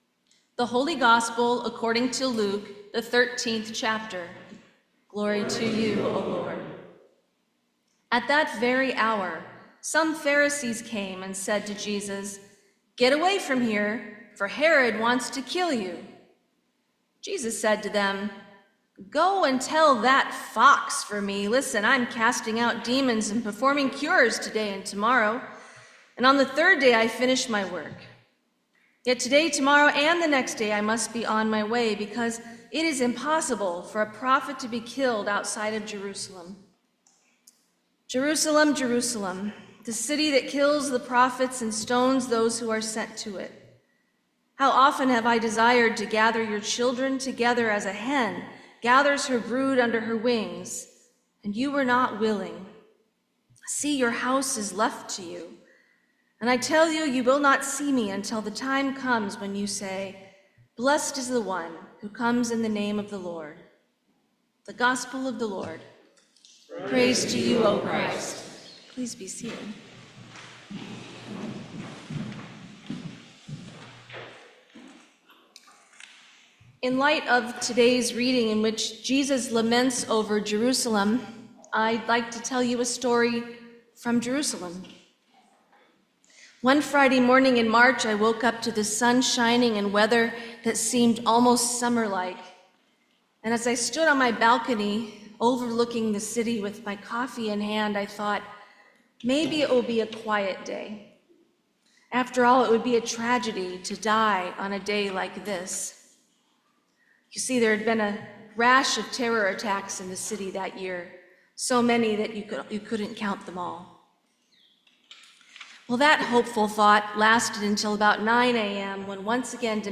Sermon for the Second Sunday in Lent 2025